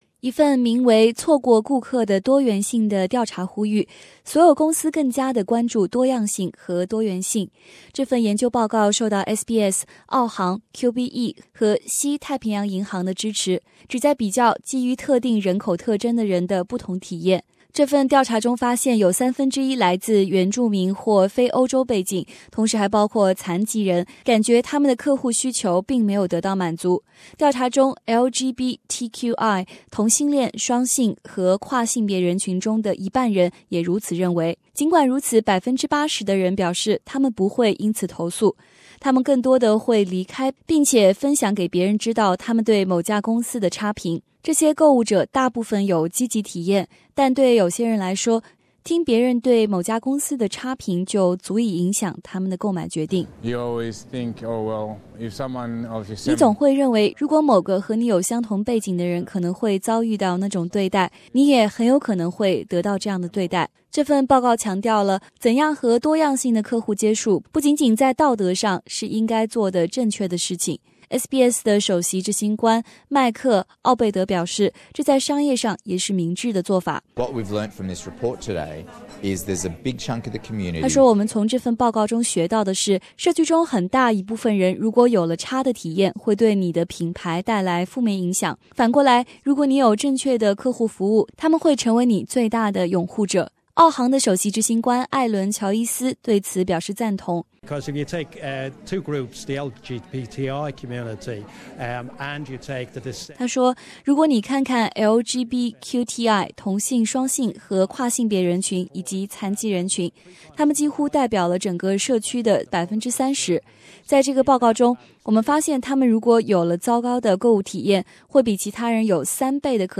最新报道